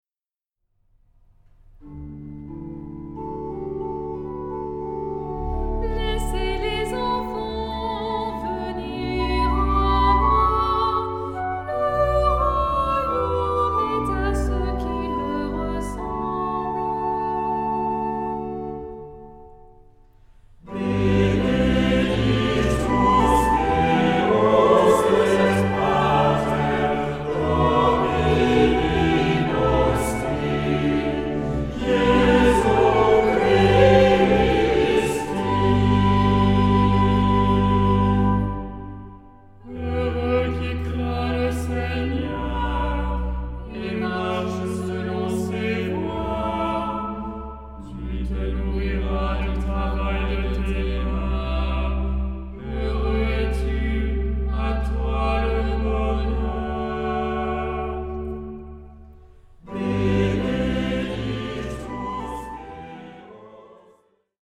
Genre-Style-Forme : Tropaire ; Psalmodie
Caractère de la pièce : recueilli
Type de choeur : SAH  (3 voix mixtes )
Instruments : Orgue (1) ; Instrument mélodique (1)
Tonalité : sol majeur ; ré majeur
interprété par Alto solo
Flûte
Quatuor de solistes